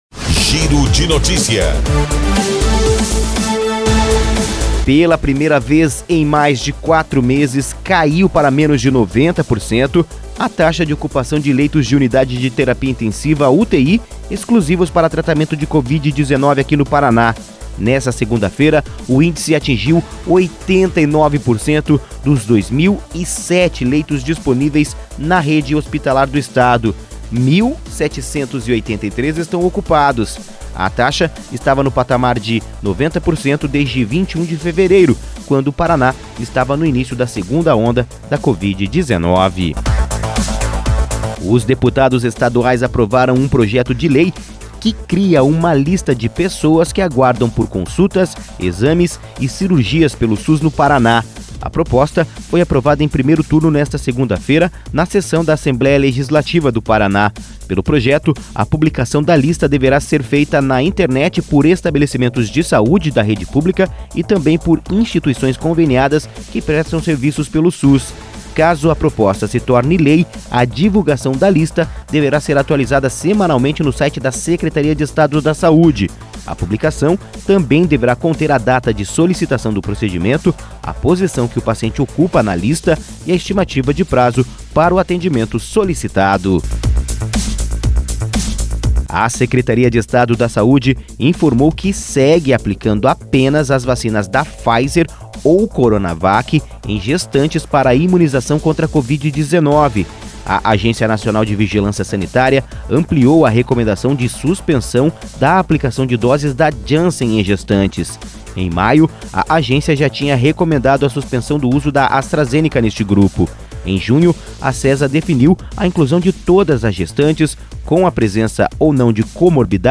Associação das Emissoras de Radiodifusão do Paraná